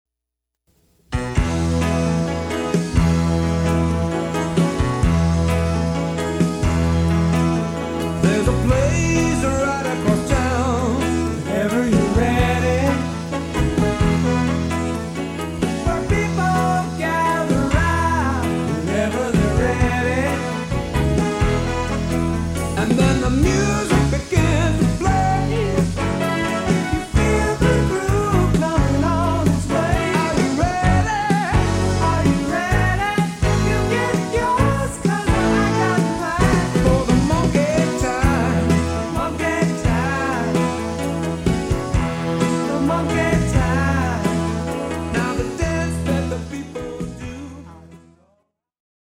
rhythm guitar
groovy track.  Recorded at TRS in Sunnyvale CA.